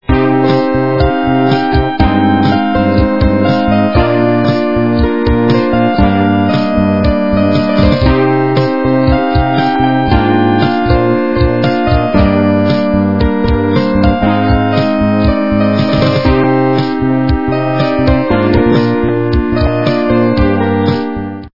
- западная эстрада
качество понижено и присутствуют гудки